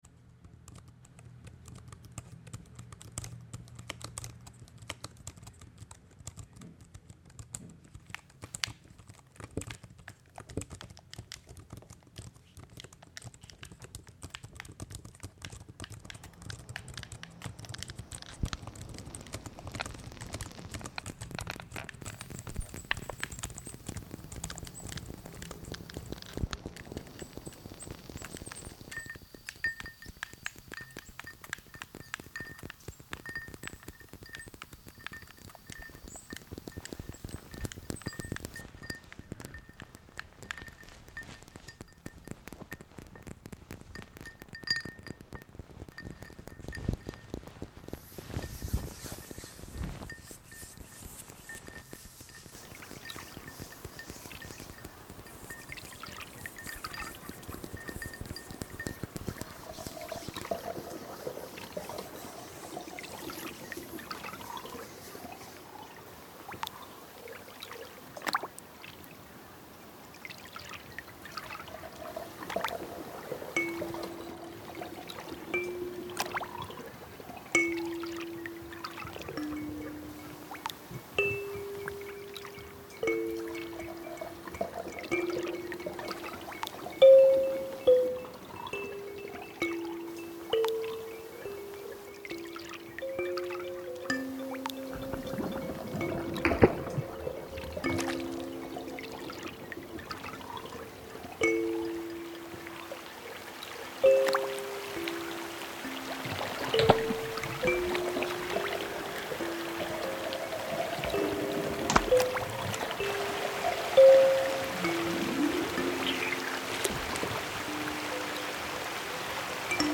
electronics
I asked 20 people what their favourite sounds are and then recorded those sounds to create this piece!